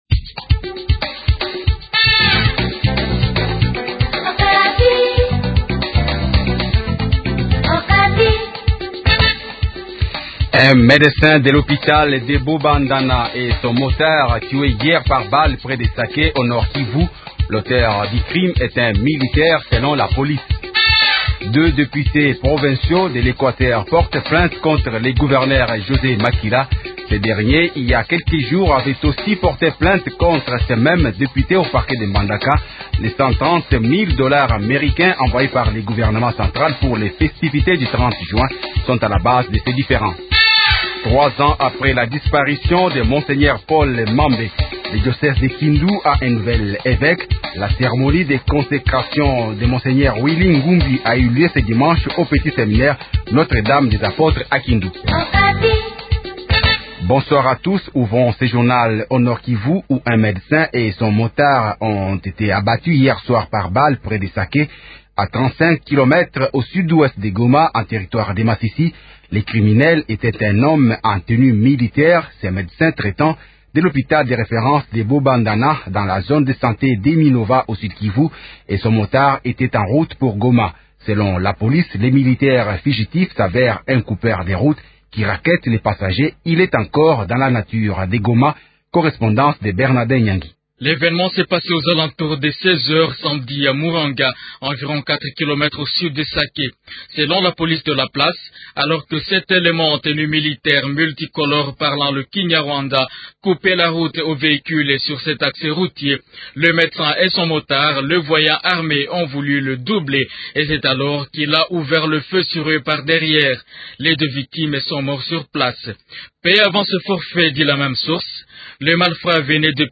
Journal Français Soir 18h00